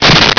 Sfx Crash Wood
sfx_crash_wood.wav